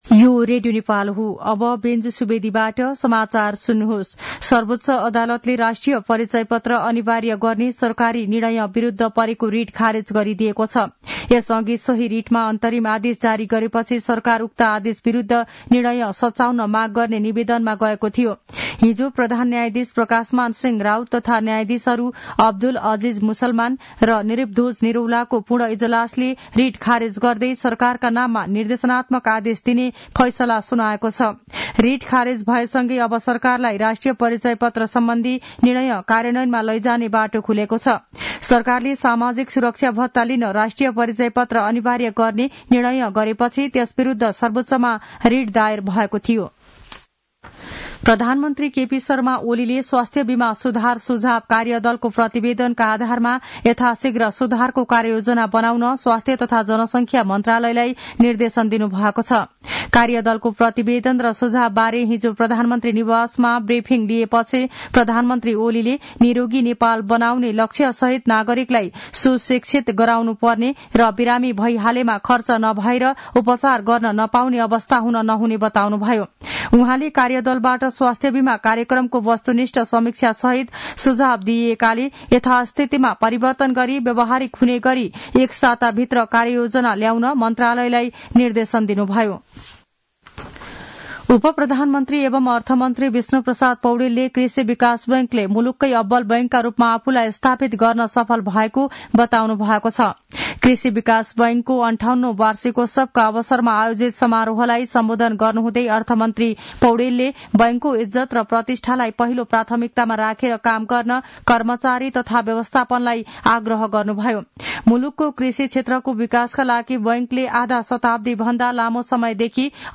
An online outlet of Nepal's national radio broadcaster
मध्यान्ह १२ बजेको नेपाली समाचार : ८ माघ , २०८१